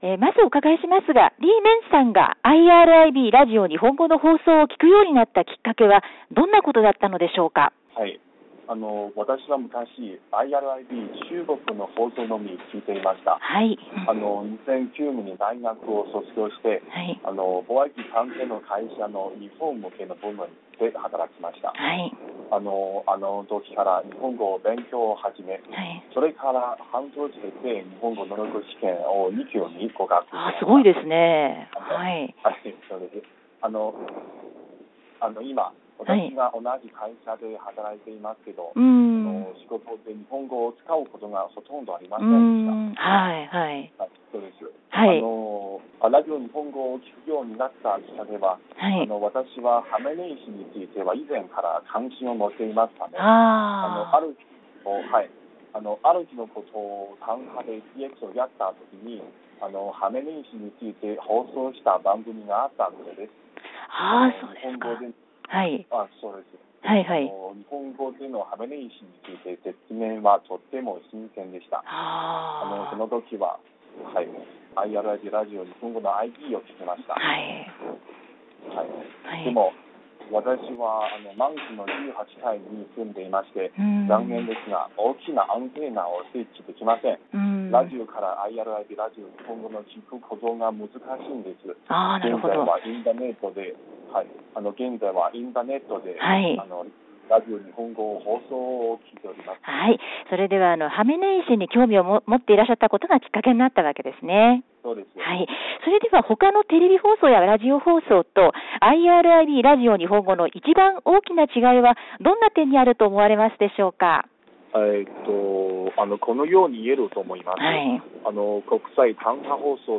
リスナーインタビュー(前半)
今週はリスナーインタビュー(前半)をお届けします。